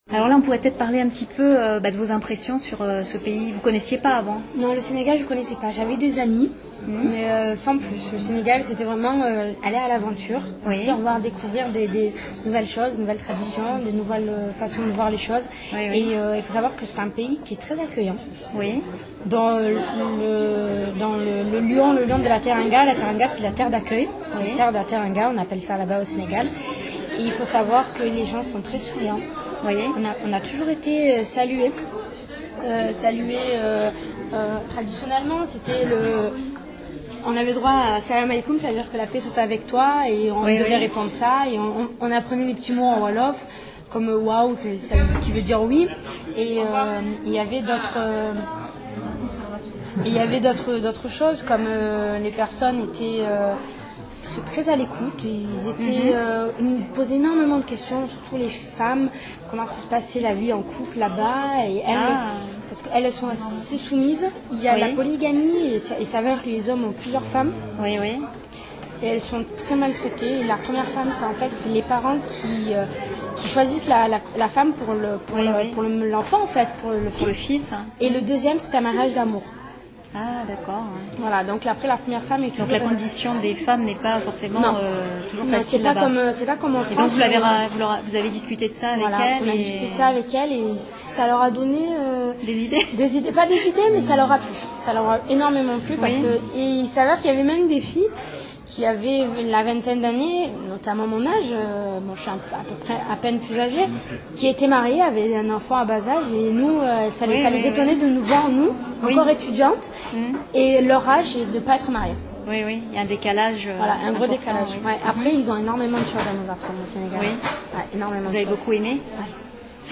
2. on : comme souvent à l’oral, « on » remplace « nous ».